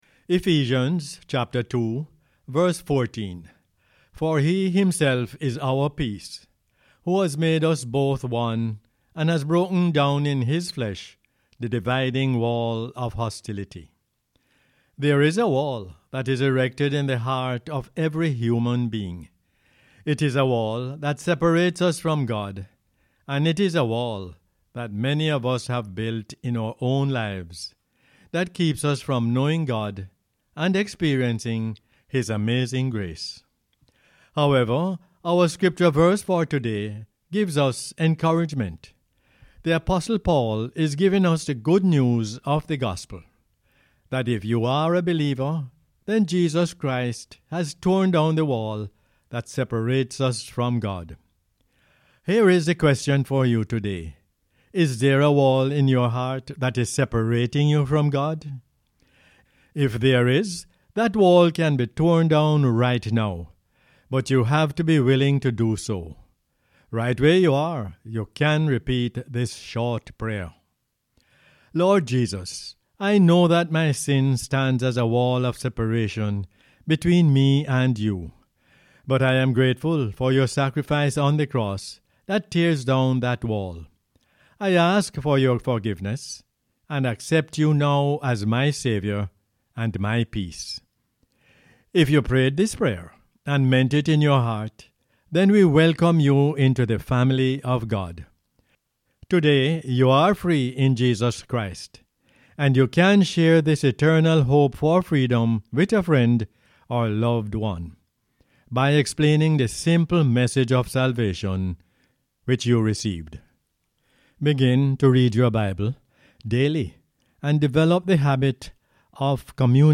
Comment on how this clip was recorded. Ephesians 2:14 is the "Word For Jamaica" as aired on the radio on 5 June 2020.